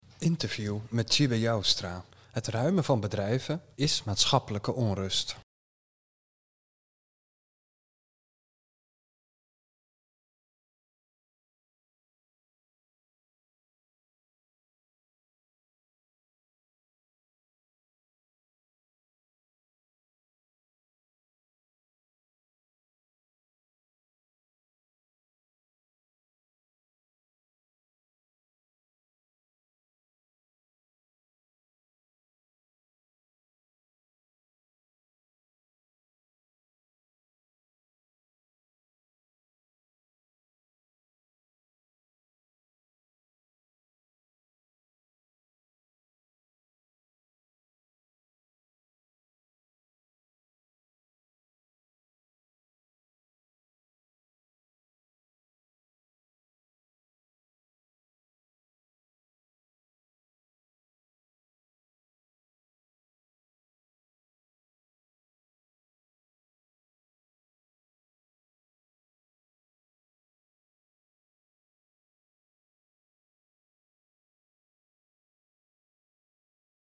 Interview met Tjibbe Joustra